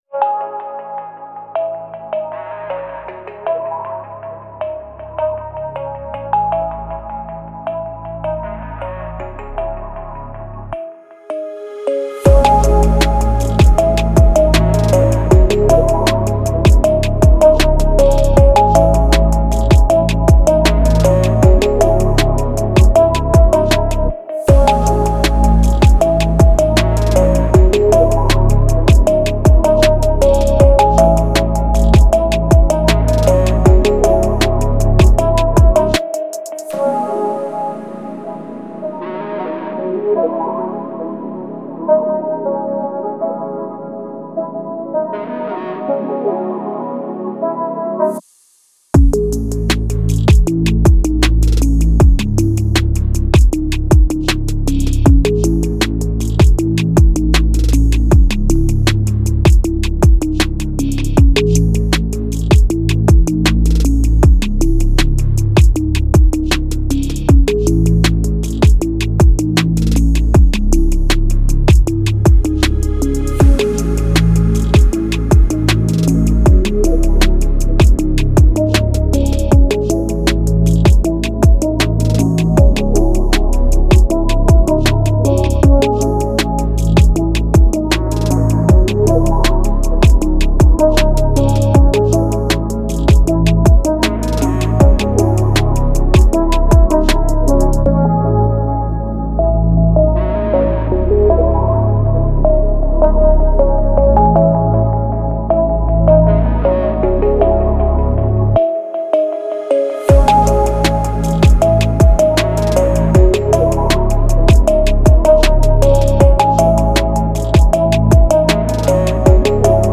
спокойная музыка
музыка без слов